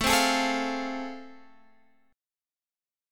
AmM7#5 chord